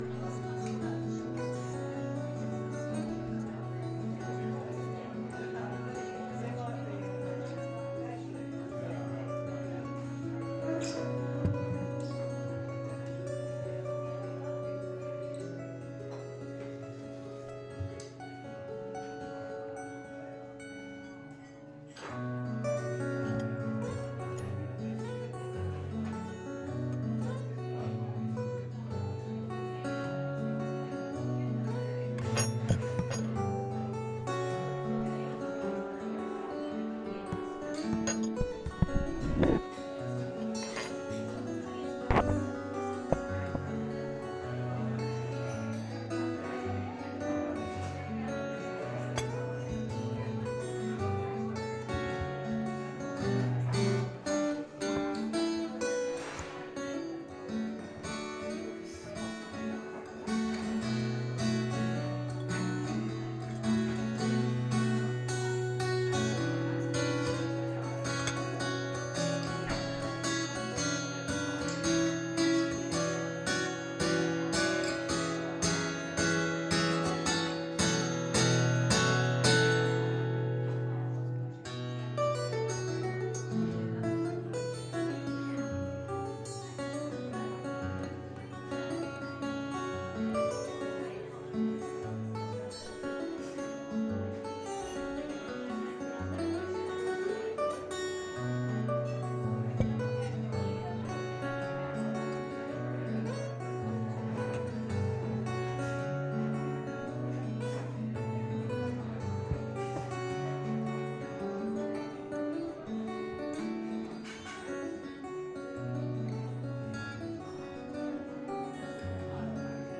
Guitar in The Fold Cafe at the end of Earthfest 2012